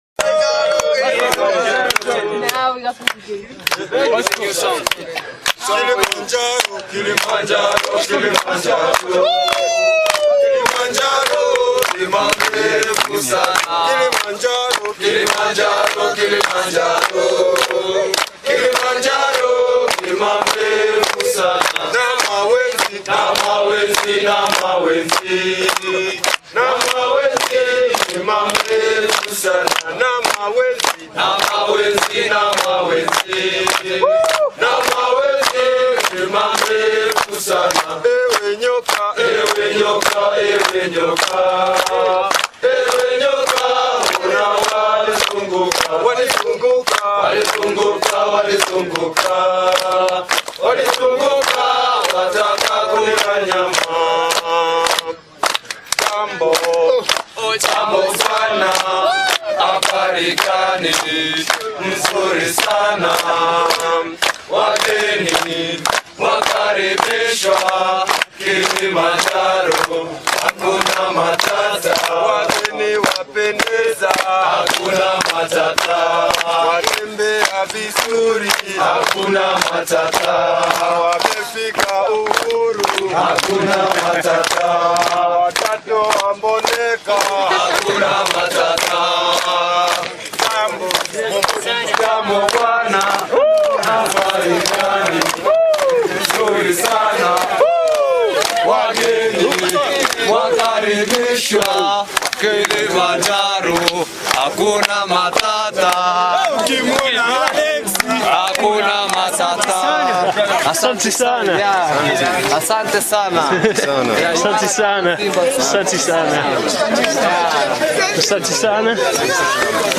Das Kilimanjaro Trägerlied wurde von unserer Begleitmannschaft immer bei unserem Empfang im Lager gesungen und dazu getanzt:
Kilimanjaro (Live vom Kilimanjaro).mp3